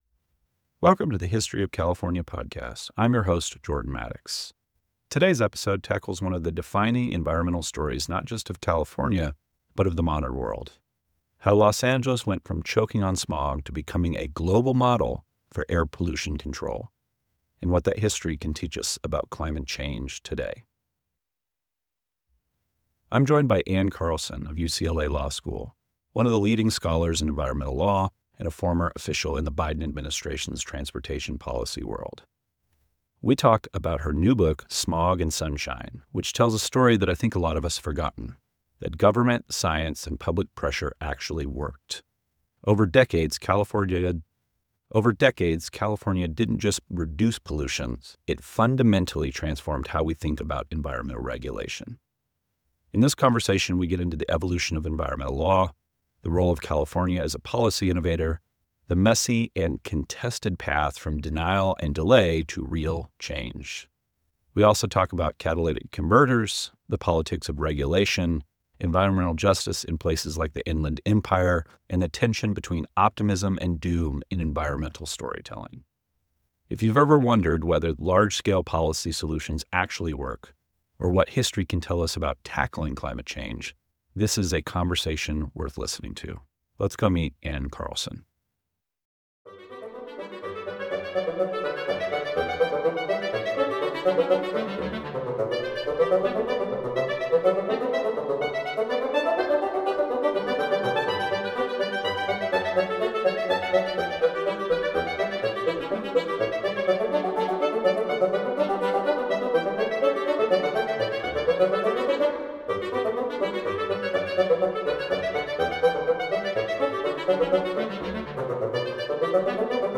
sits down with Ann Carlson to explore the history and legacy of air pollution regulation in California, as told in her book Smog and Sunshine. The conversation traces the transformation of Los Angeles from one of the most polluted regions in the United States to a global leader in environmental policy.